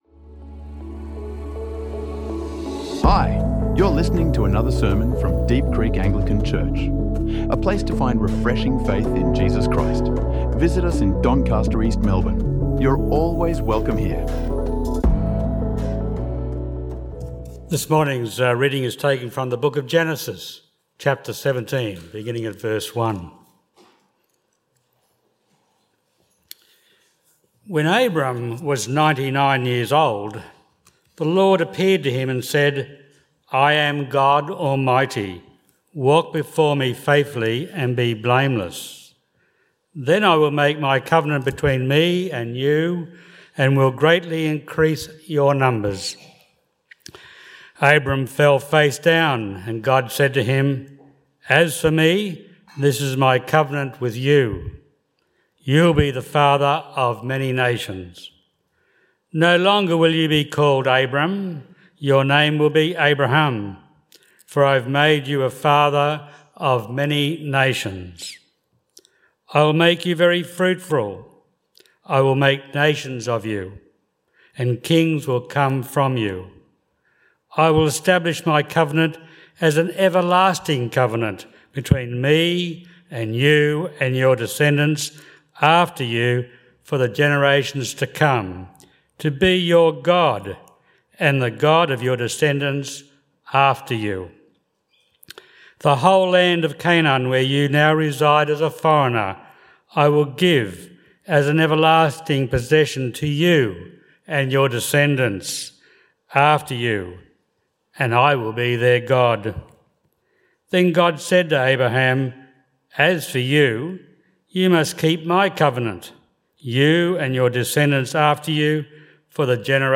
In this sermon on Genesis 17, we explore how God breaks thirteen years of silence to reveal Himself as El Shaddai, God Almighty. Discover how walking with God requires surrendering our control to trust His all-sufficient character.